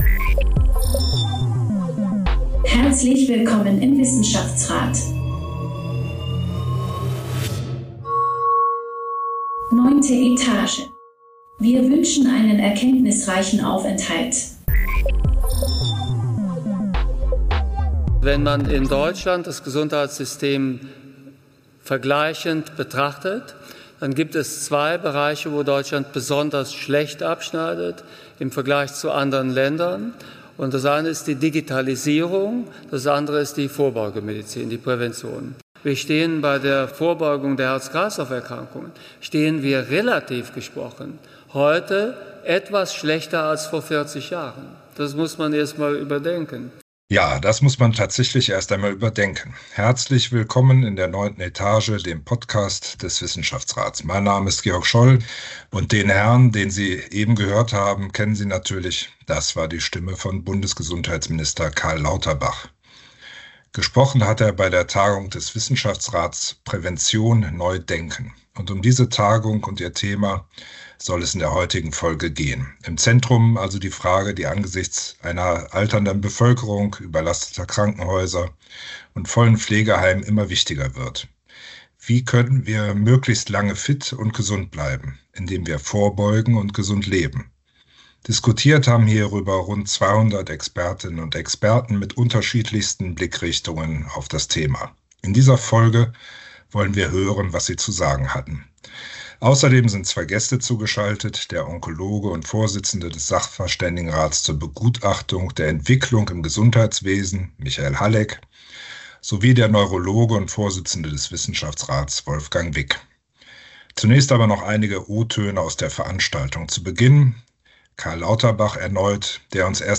Mit Ausschnitten aus den Symposiums-Vorträgen analysieren wir gemeinsam die verschiedenen Blickwinkel und Positionen der Podiumsgäste, fassen die wichtigsten Aspekte zusammen und blicken auf die nächsten Schritte einer dringend nötigen Initiative für Prävention und Gesundheit.